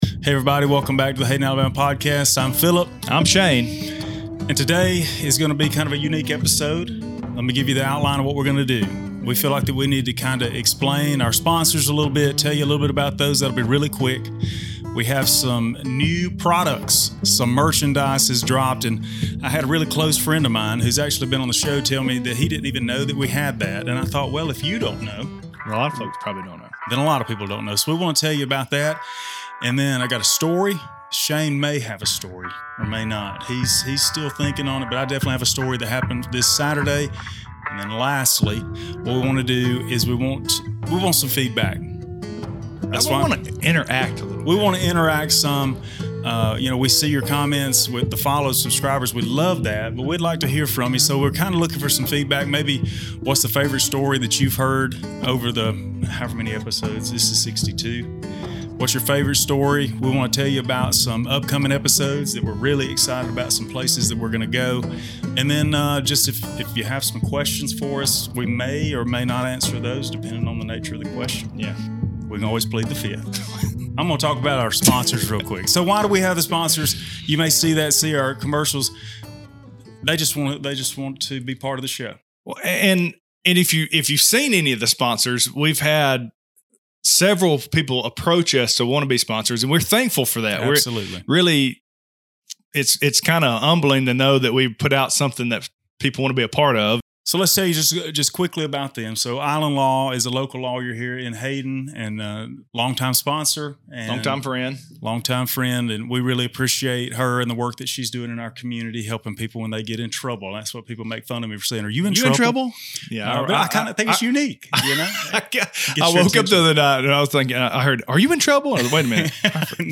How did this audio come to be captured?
This week the Hayden Alabama Podcast went LIVE on Facebook. What you are about to watch is the edited Live Episode 62.